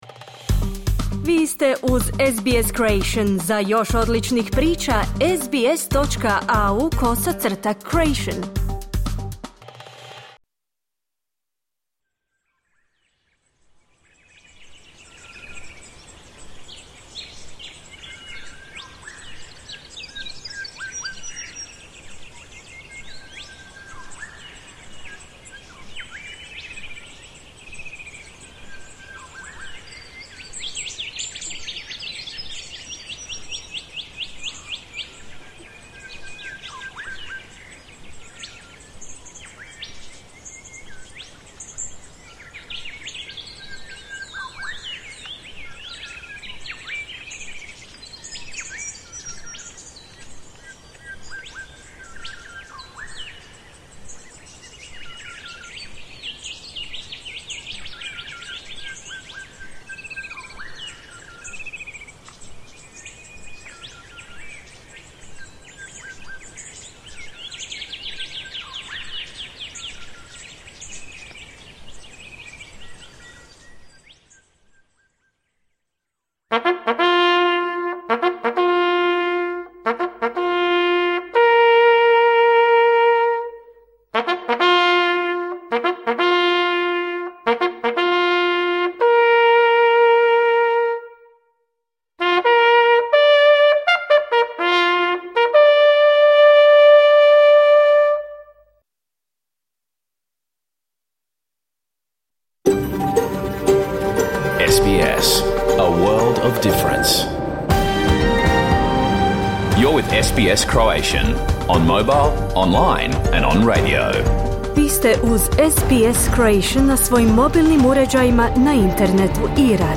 Pregled vijesti i aktualne teme iz Australije, Hrvatske i svijeta. Emitirano uživo na radiju SBS1 u ponedeljak, 11. studenog 2024.